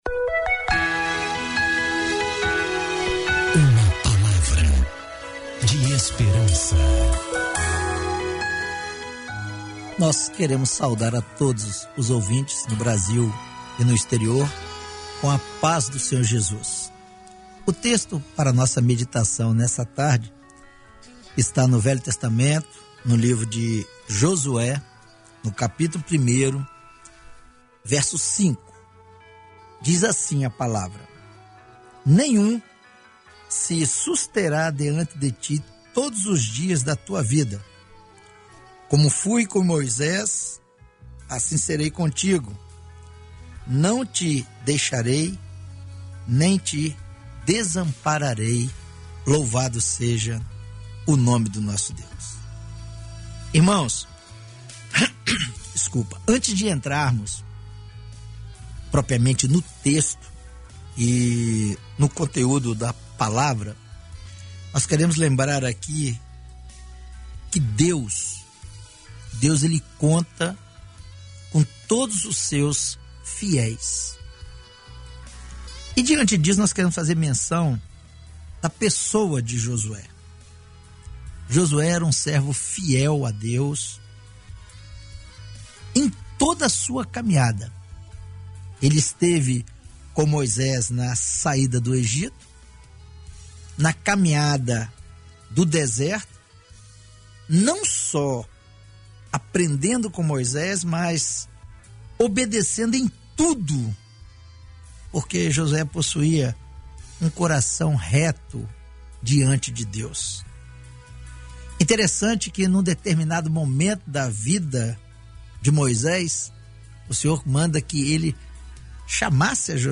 Mensagem transmitida no dia 16 de julho de 2021, dentro do programa Boa Tarde Maanaim